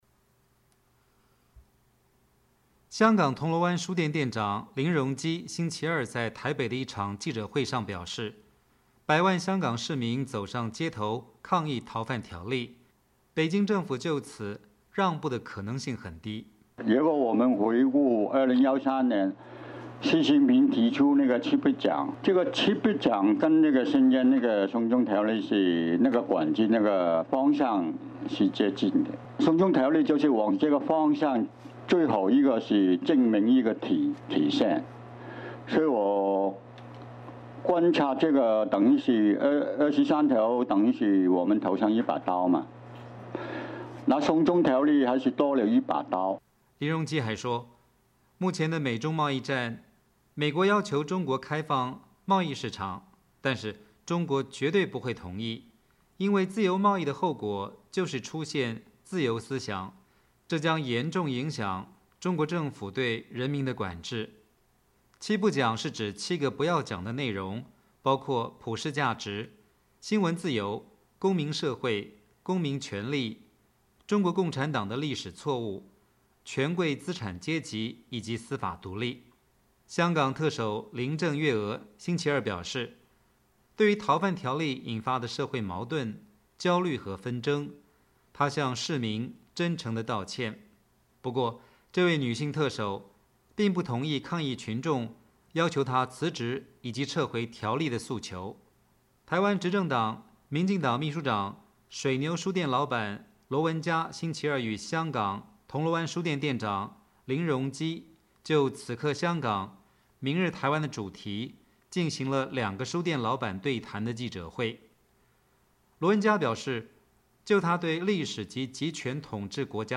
香港铜锣湾书店店长林荣基星期二(6月18日)在台北的一场记者会上表示，百万香港市民走上街头抗议逃犯条例，北京政府就此让步的可能性很低。
台湾执政党民进党秘书长、水牛书店老板罗文嘉星期二与香港铜锣湾书店店长林荣基就“此刻香港。明日台湾”的主题进行了两个书店老板对谈的记者会。